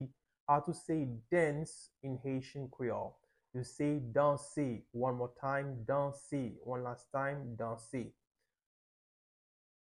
Pronunciation:
3.How-to-say-Dance-in-Haitian-Creole-–-danse-with-pronunciation.mp3